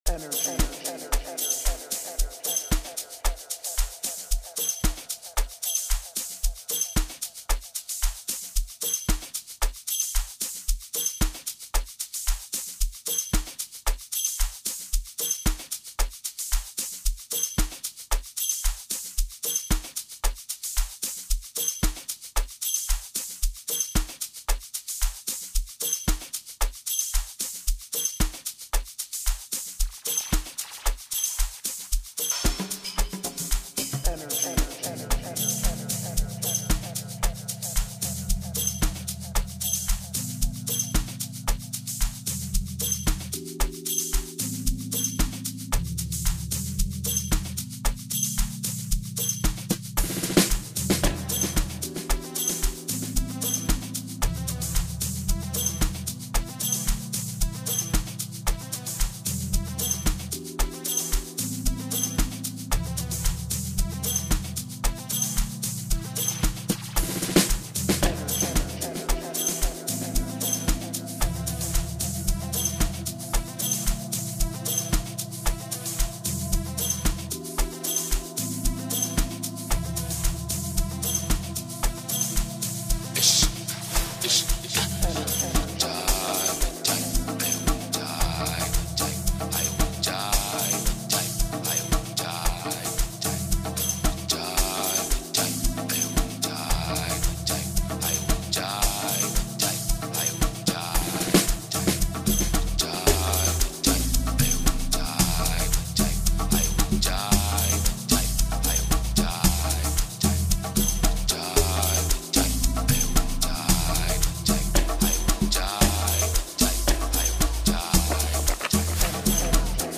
Get this energizing song